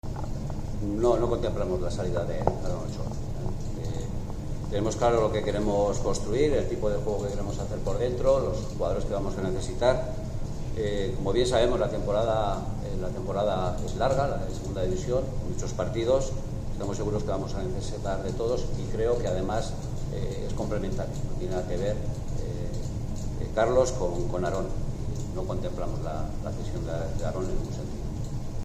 ha comparecido en la mañana de este jueves en sala de prensa en el estadio de La Rosaleda.